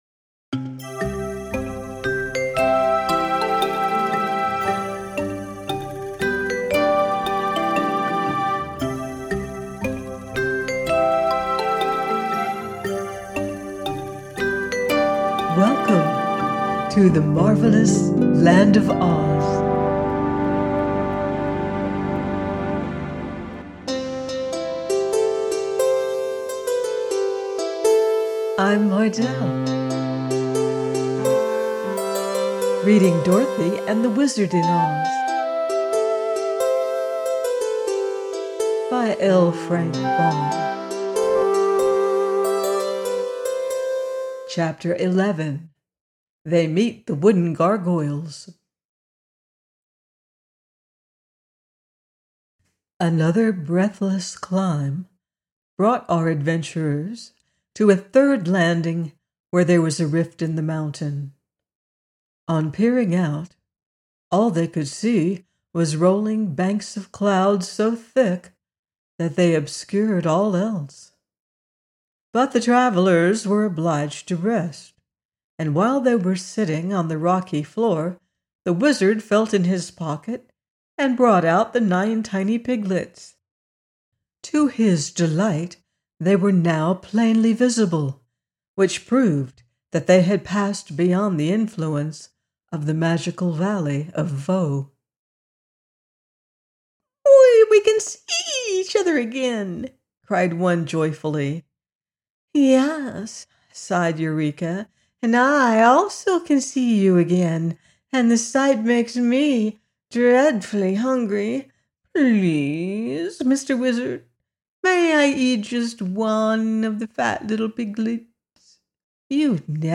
DOROTHY AND THE WIZARD IN OZ: by L. Frank Baum - audiobook